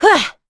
Hilda-Vox_Attack3_b.wav